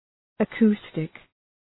Προφορά
{ə’ku:stık}